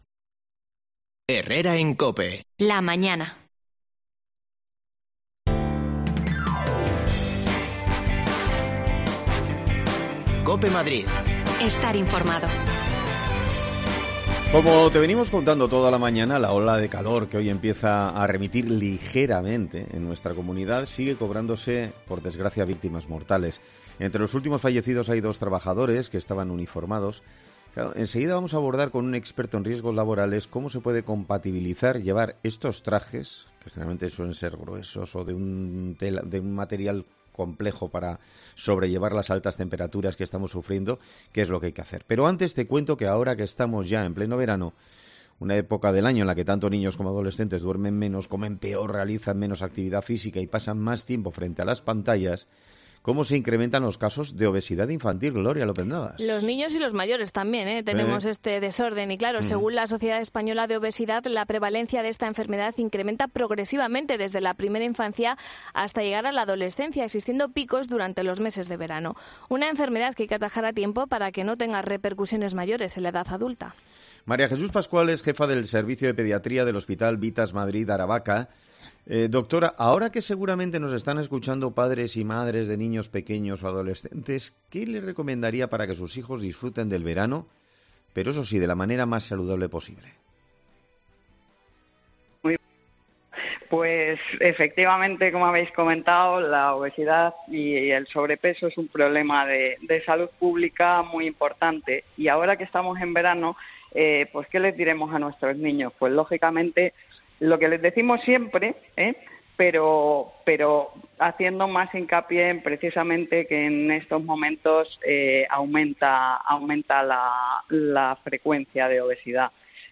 AUDIO: Hablamos con un experto en prevención de riesgos laborales sobre la compatibilidad de los uniformes de trabajo con la ola de calor, que ya se...
Las desconexiones locales de Madrid son espacios de 10 minutos de duración que se emiten en COPE, de lunes a viernes.